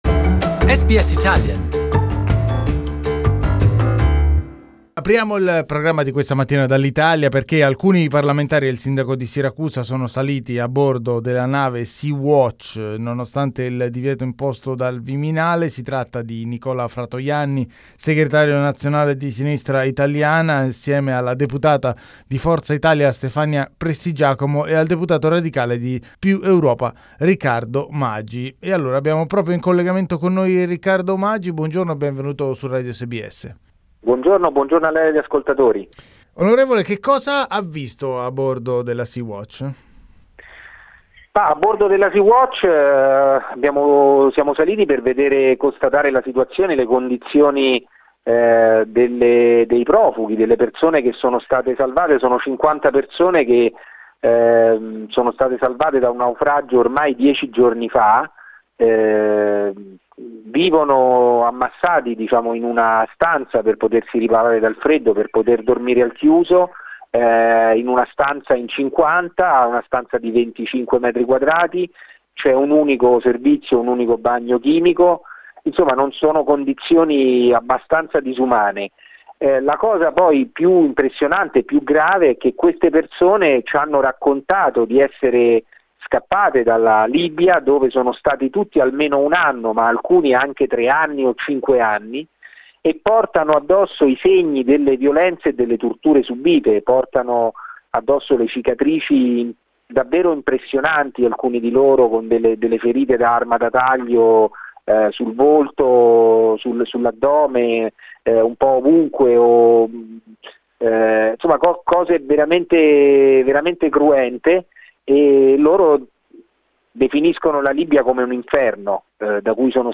We interviewed one of these MPs, +Europa's Riccardo Magi, who told us that the almost 50 African migrants live in precarious conditions in one small room with just one toilet.